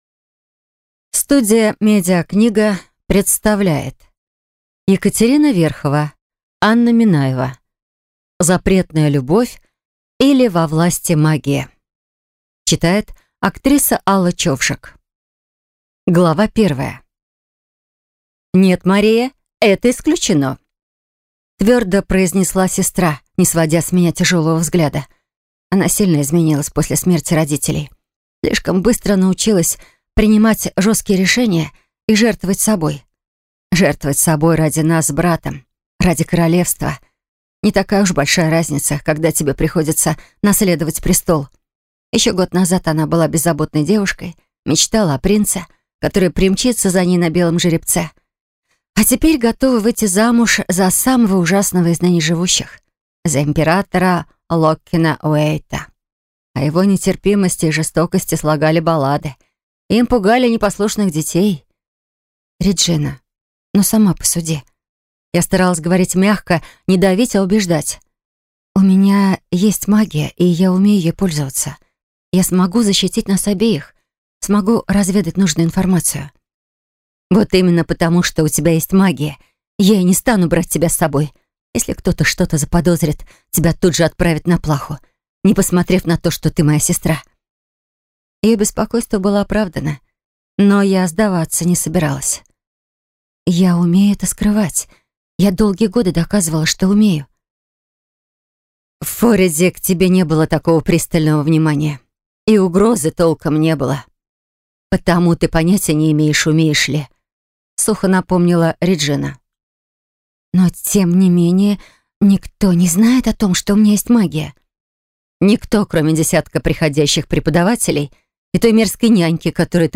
Аудиокнига Запретная любовь, или Во власти магии | Библиотека аудиокниг